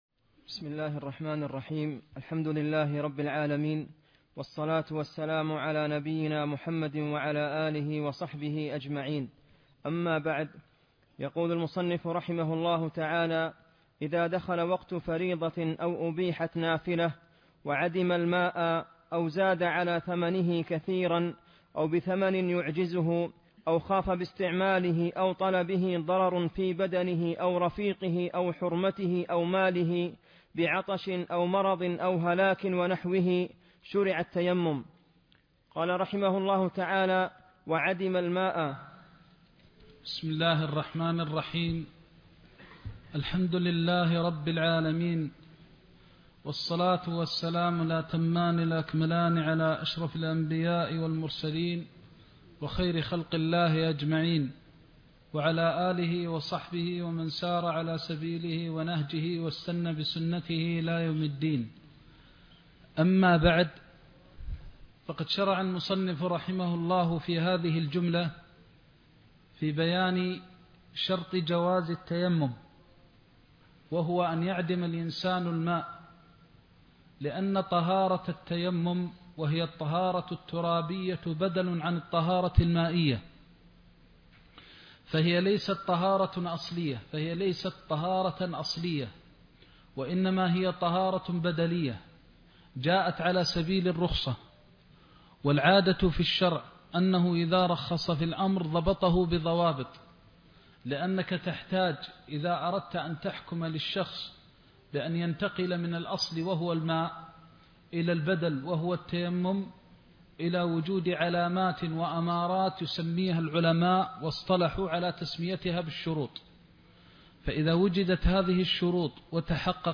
زاد المستقنع كتاب الطهارة (24) درس مكة